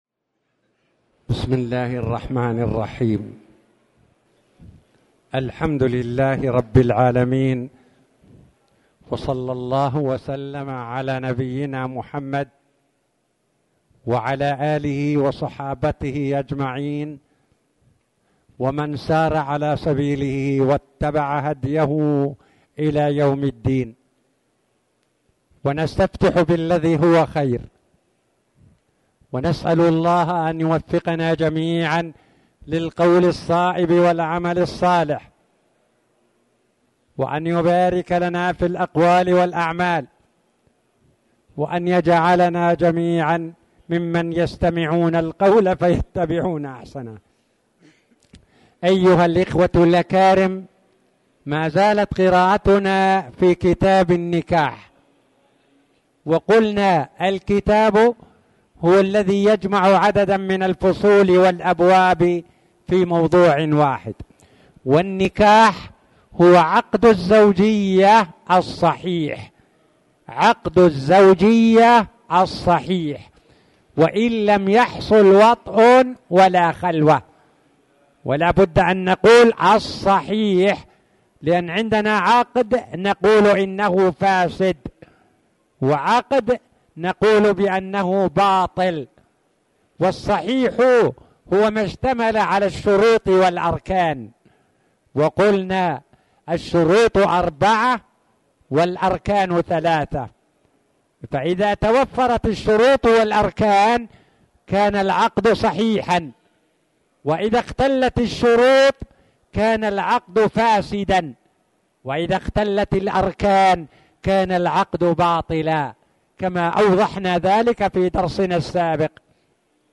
تاريخ النشر ٢٢ صفر ١٤٣٨ هـ المكان: المسجد الحرام الشيخ